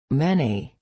(ˈmɛni)   muitos